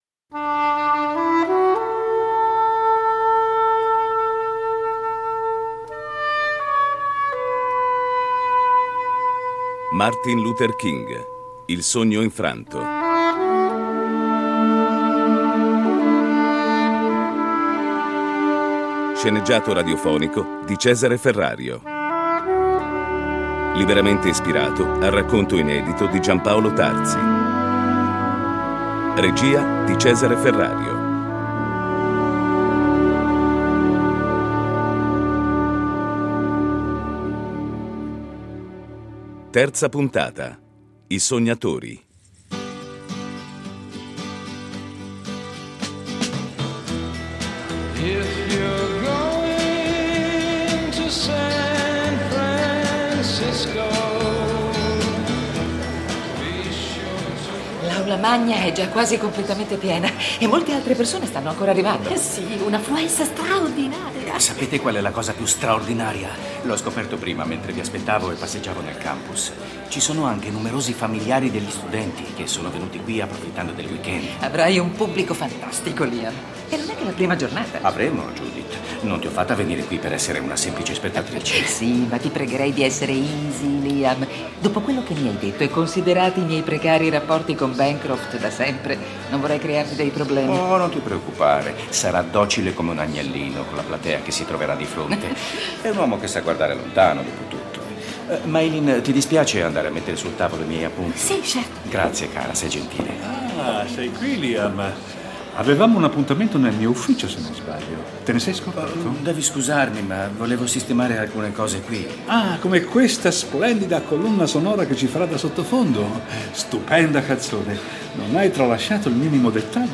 Riproporre a mezzo secolo di distanza uno sceneggiato nel quale sono presenti i necessari riferimenti biografici, ma soprattutto una nuova chiave di